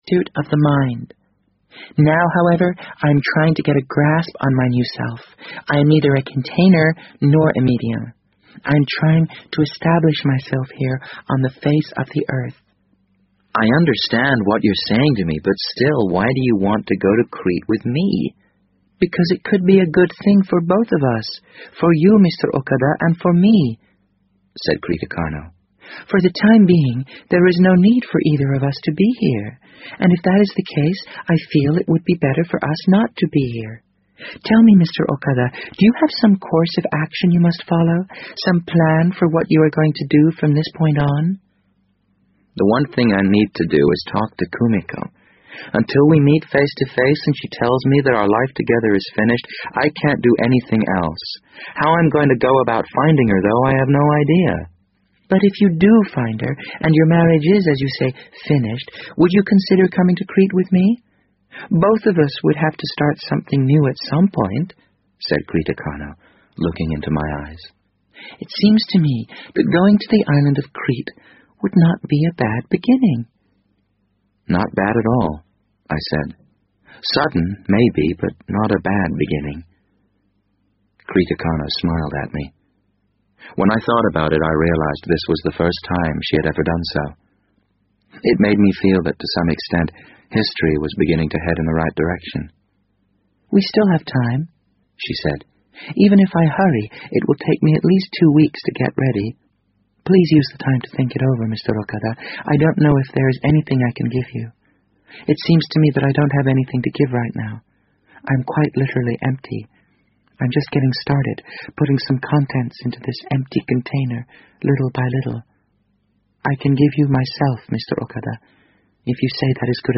BBC英文广播剧在线听 The Wind Up Bird 008 - 13 听力文件下载—在线英语听力室